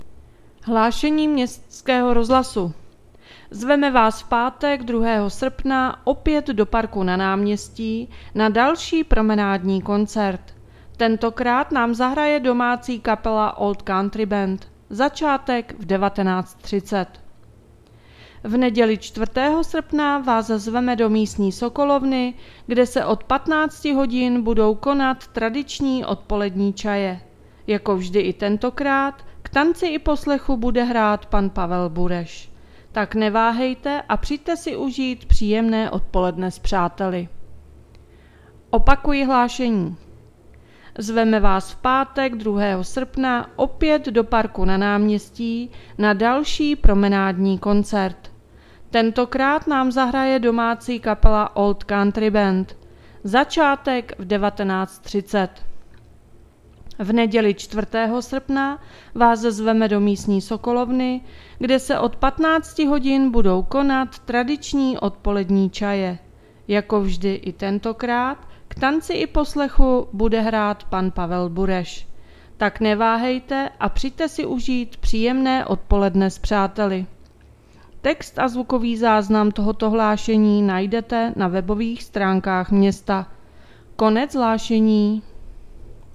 Hlášení městského rozhlasu 31.7.2024